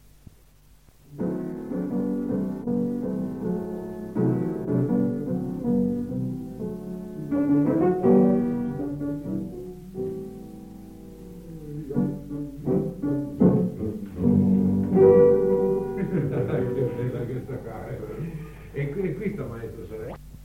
pianoforte